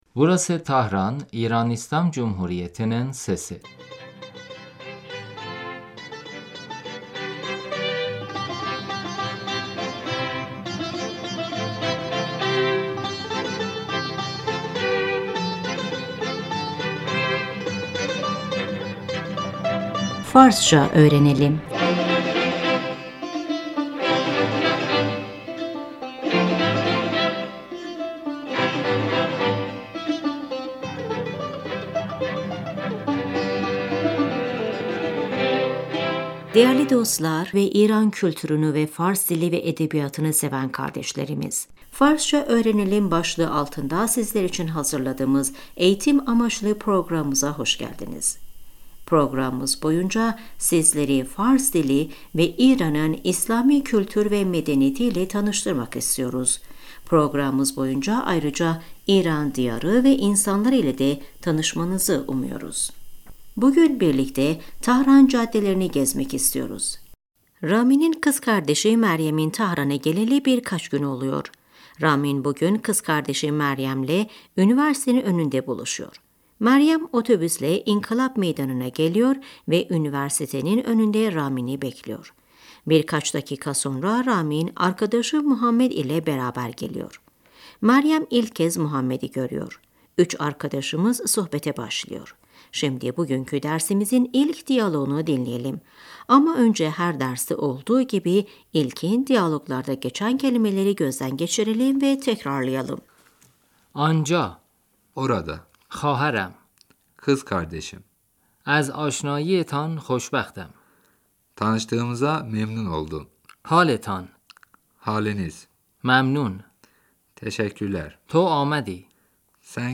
صدای تردد خودروها در خیابان Caddede araçların sesi رامین - اِه ...